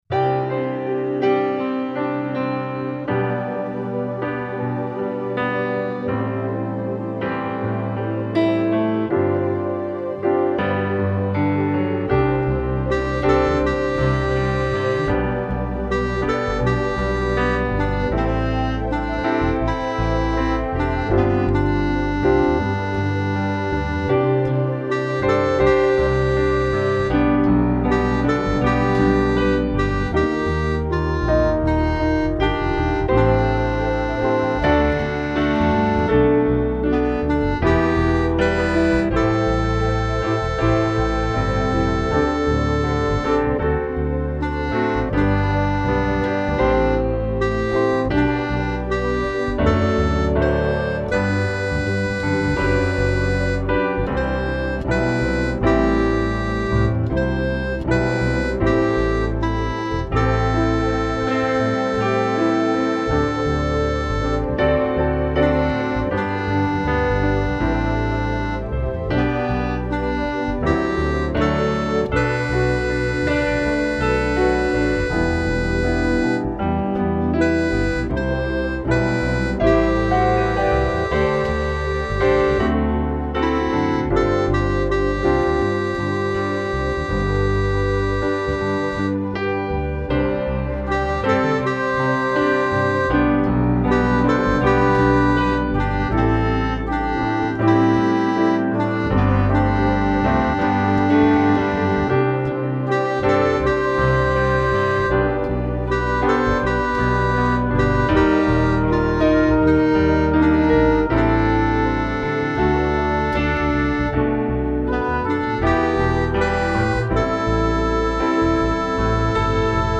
It has a lovely harmony that kicks in from verse two.
My backing has the harmony line as well.